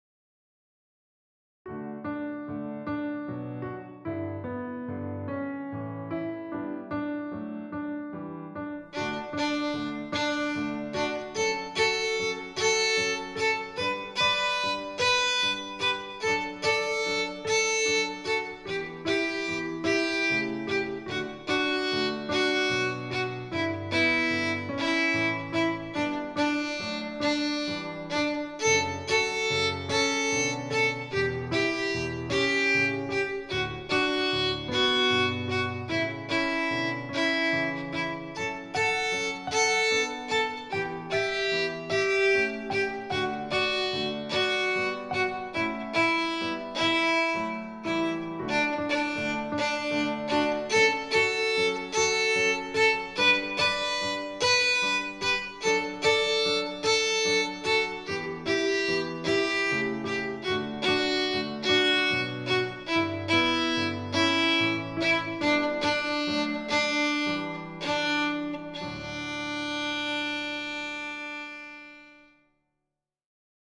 Twinklepated is a syncopated variation of Twinkle Twinkle Little Star.
This Twinkle etude – Twinklepated is set in 3/4 time matching the time signature of the Boccherini Minuet.
Viola – D Major